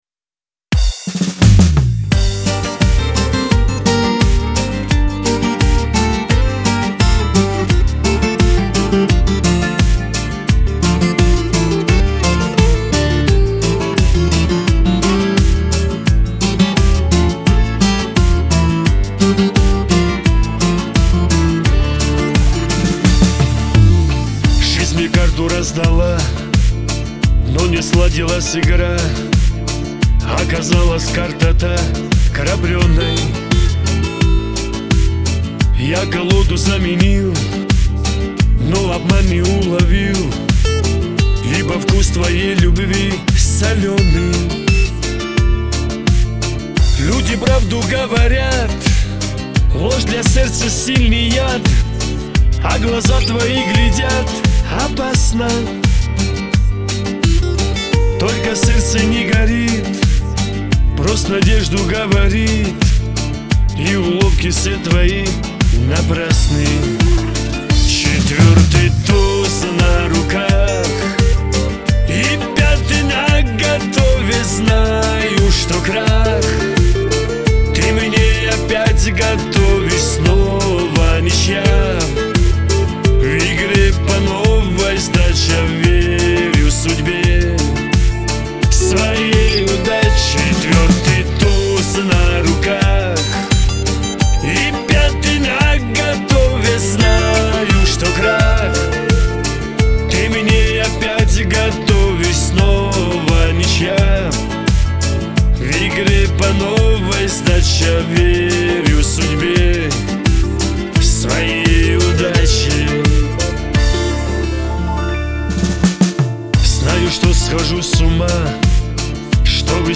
Лирика
Кавказ поп